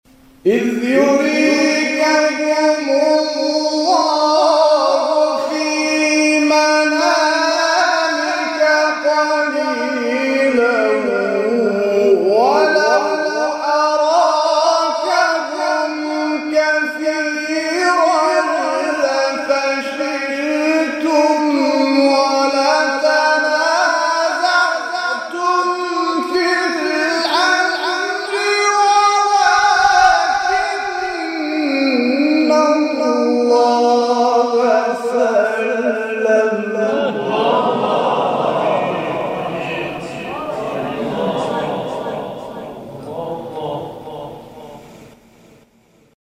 سوره انفال در مقام بیات